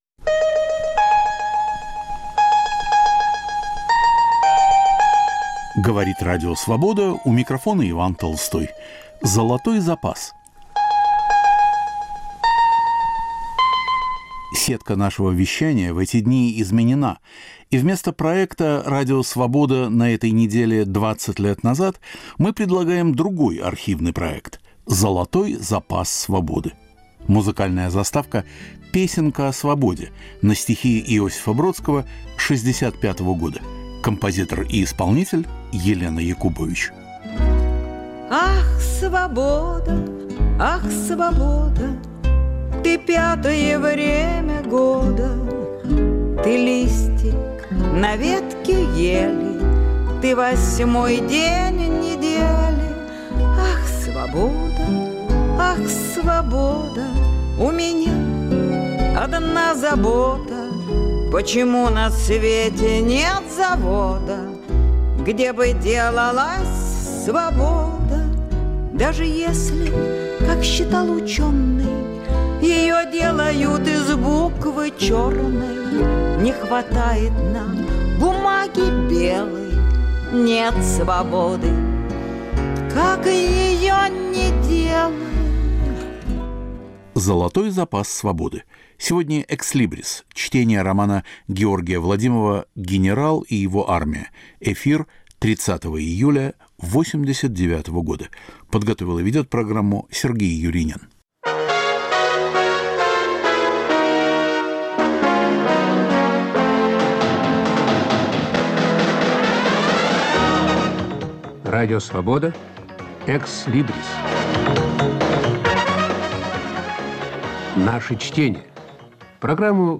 Чтение романа Георгия Владимова "Генерал и его армия". Читает Юлиан Панич в присутствии автора, разъясняющего особенности сюжета.